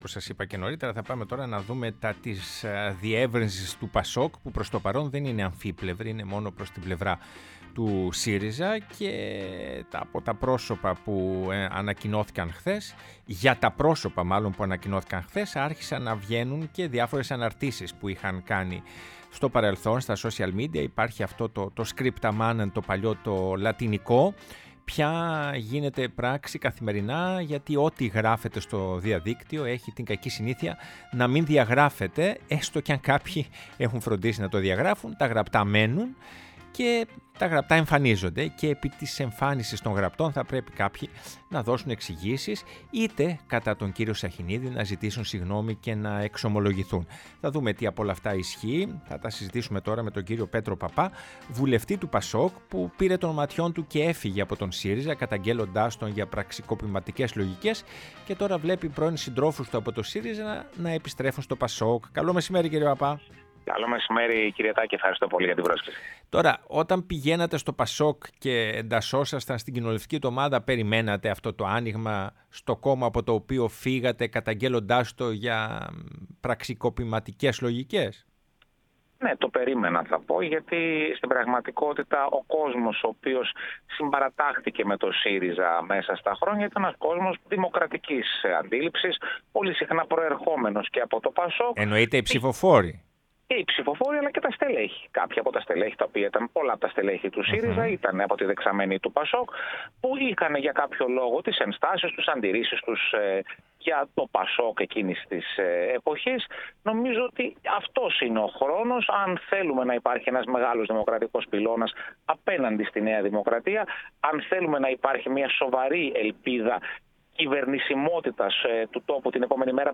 Πέτρος Παππάς, βουλευτής ΠΑΣΟΚ-ΚΙΝΑΛ μίλησε στην εκπομπή «Ναι, μεν Αλλά»